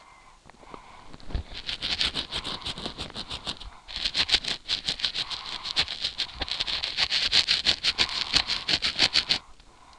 scratching.wav